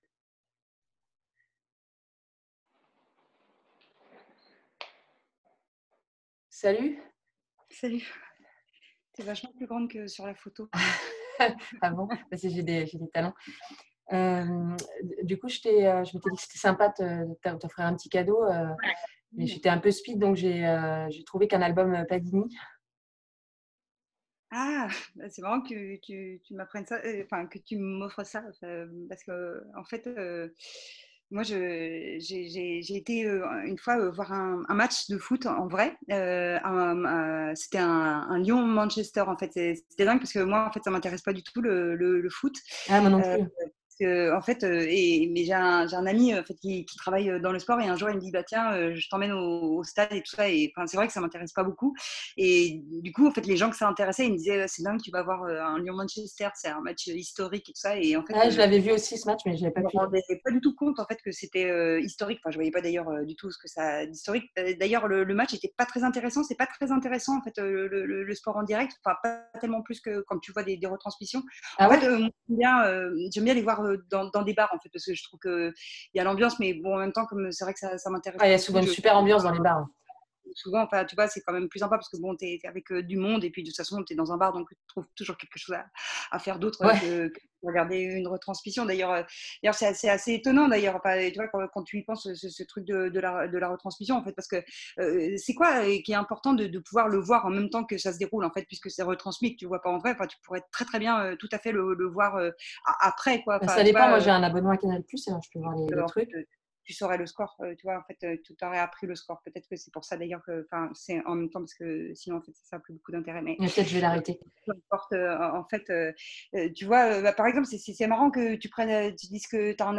"Love Plugged" est un spectacle audio en capsules.
Des fragments de "théâtre improvisé" immatériels, basés sur les relations textuelles.
monologue à 2